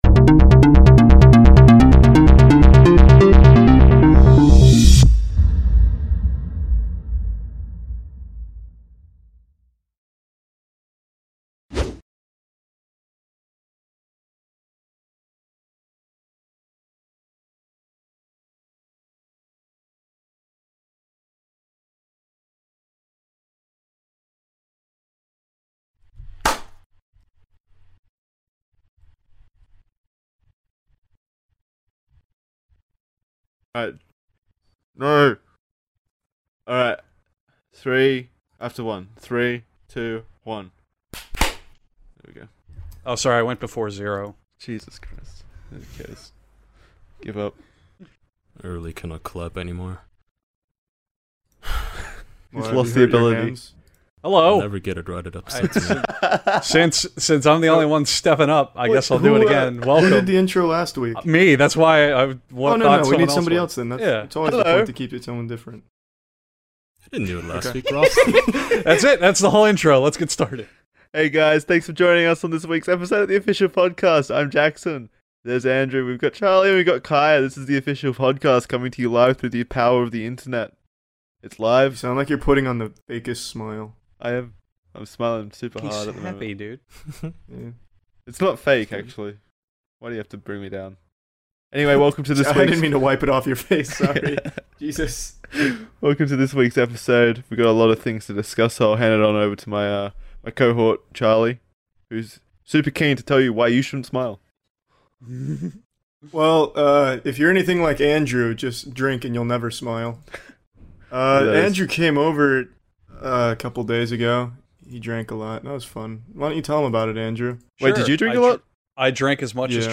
Four close man friends gather around to answer questions again.